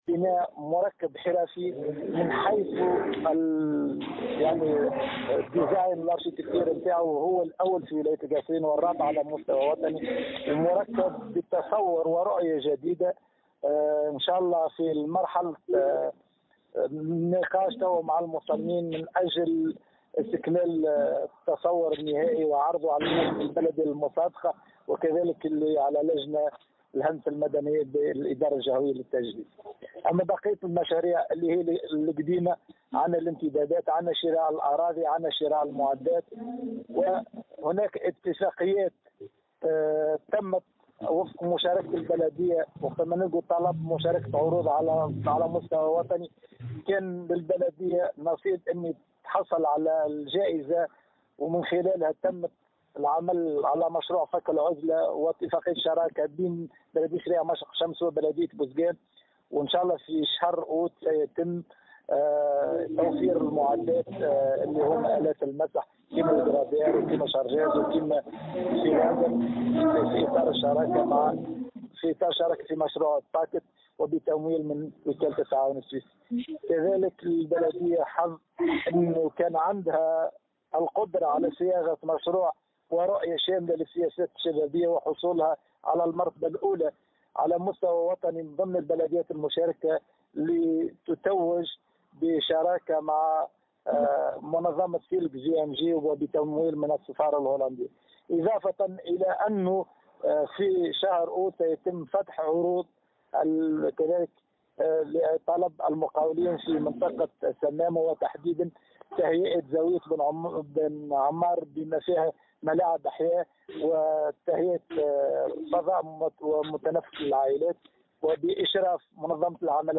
القصرين: طلب عروض للانطلاق في إنجاز عدد من المشاريع الخاصة بالبنية التحتية من بينها بناء مقر بلدية و مستودع بلدي(تصريح)
اكد الناحم الصالحي رئيس بلدية الشرايع التابعة لولاية القصرين انه سيتم غرة اوت المقبل طلب العروض للانطلاق في انجاز عديد المشاريع المتعلقة بالبنية التحتية من بينها مشروع بناء مقر بلدية و المستودع البلدي.